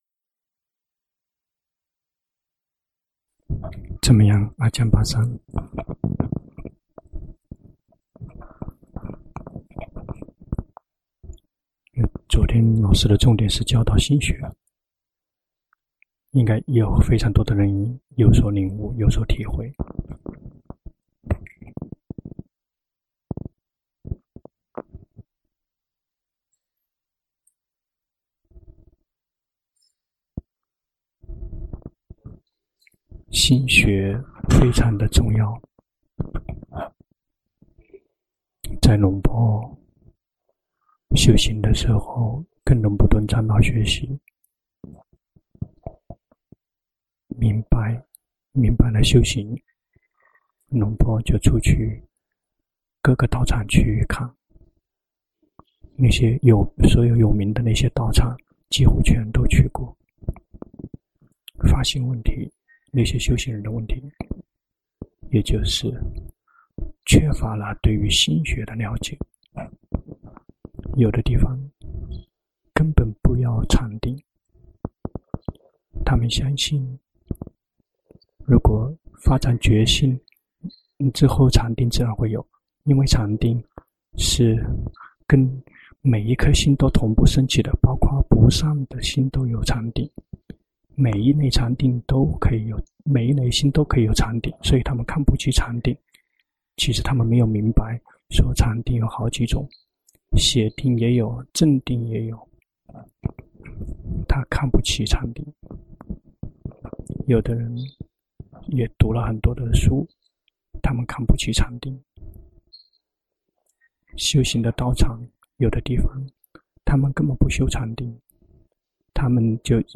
長篇法談｜心學的重要性